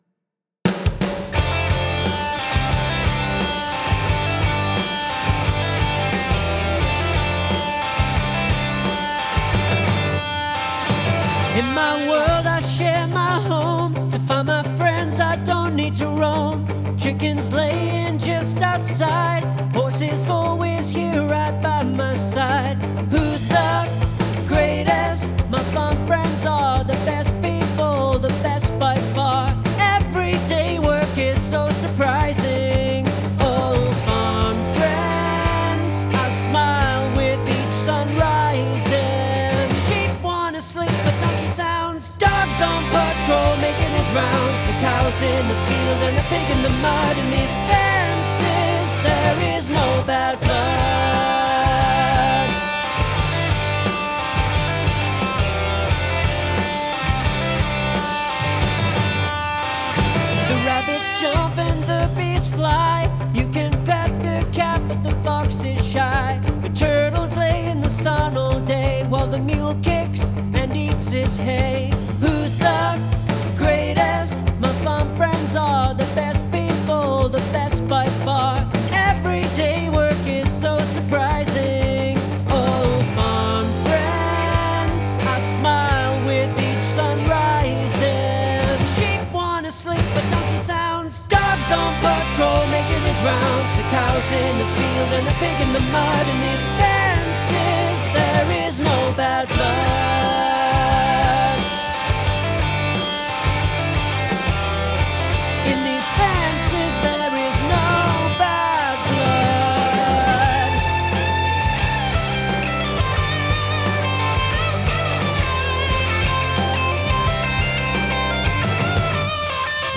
by Spintown & Company
the vocal lines are sometimes kind of awkward.